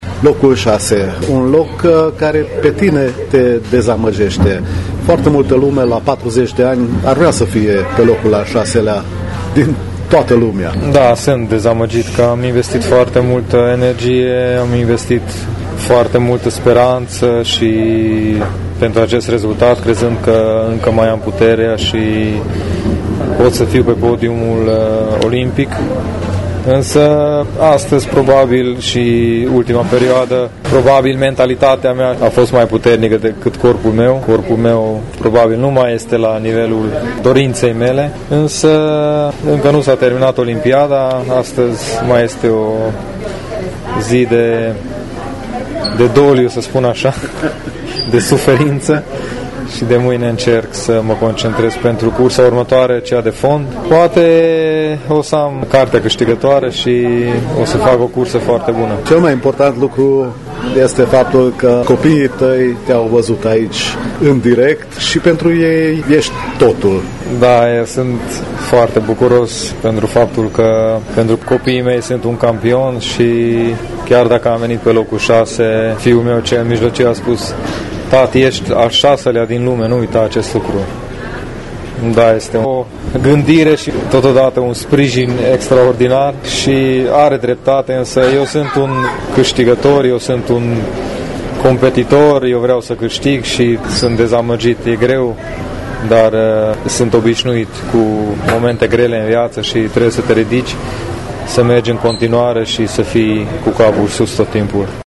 Un interviu audio
prezent la Rio